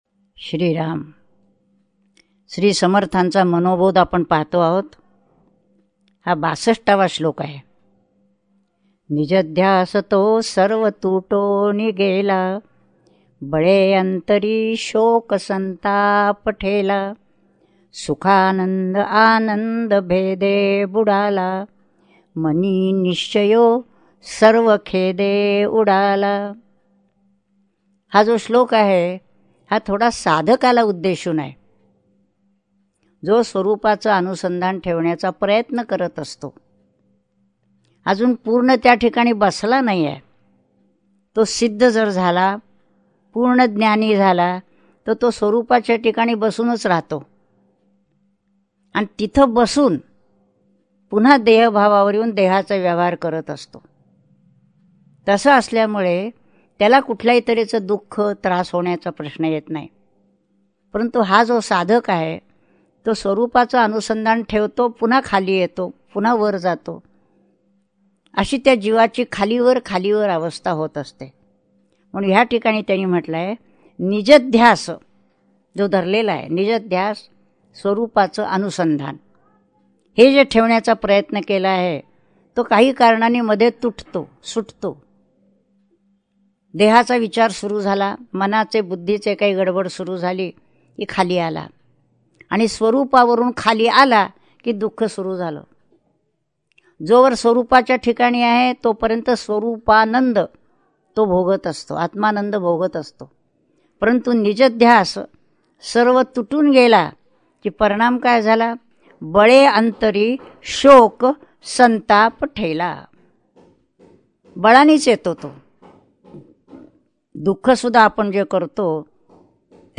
श्री मनाचे श्लोक प्रवचने श्लोक 62 # Shree Manache Shlok Pravachane Shlok 62